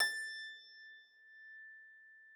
53l-pno21-A4.wav